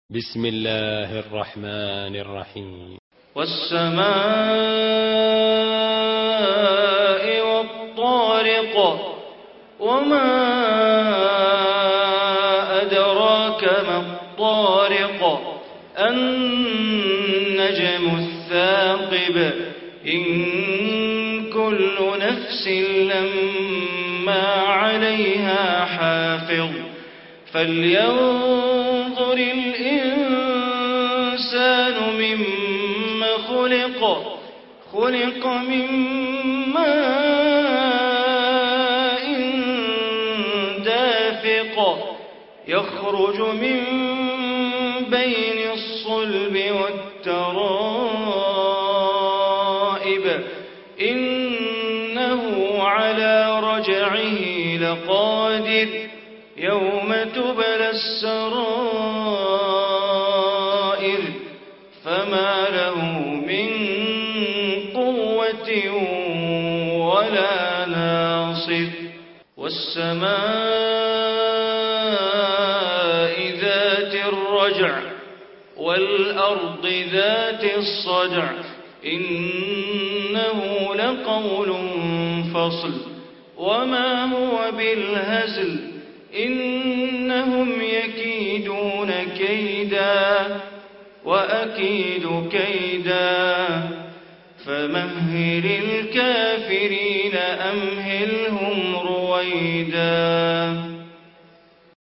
Surah Tariq Recitation by Sheikh Bandar Baleela
Surah Tariq, listen online mp3 tilawat / recitation in Arabic recited by Sheikh Bandar Baleela. Surah Tariq is 86 chapter of Holy Quran.